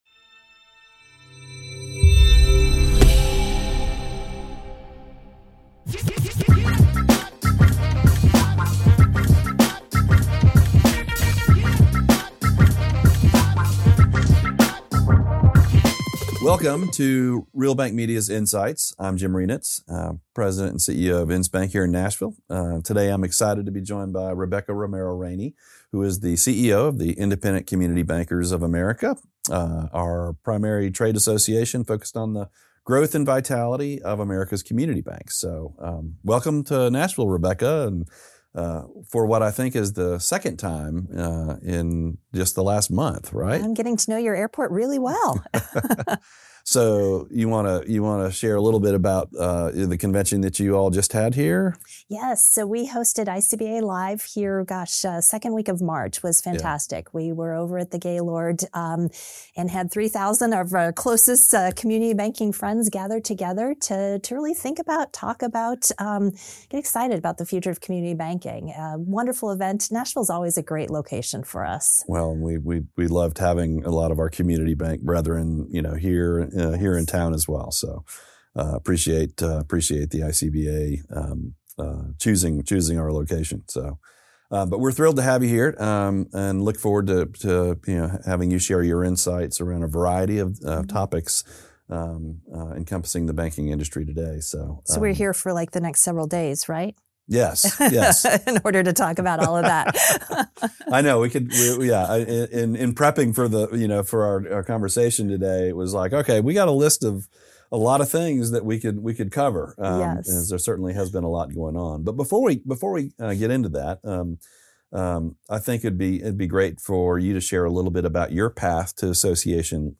Listen to these two great women pour their hearts out in the latest INSights conversation from Reel Bank Media, powered by INSBANK.